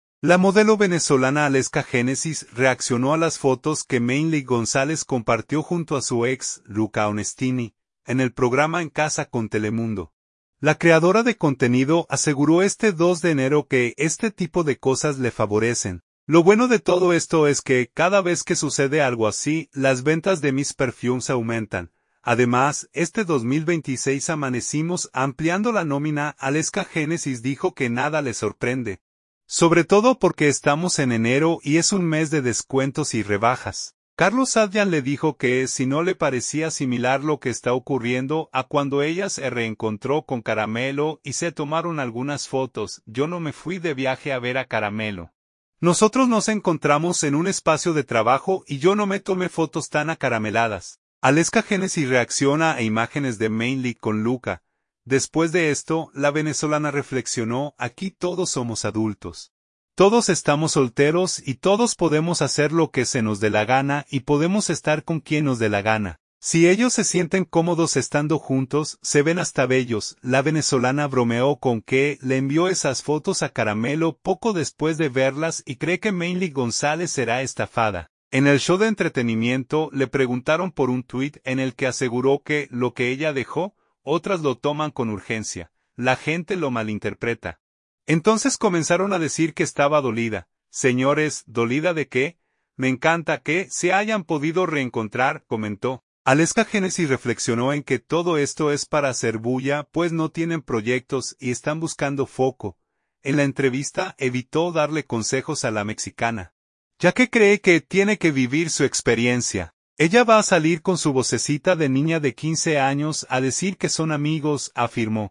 Aleska Génesis habló en el programa En Casa con Telemundo de las fotos de Manelyk González y Luca Onestini, con quienes compartió en La Casa de los Famosos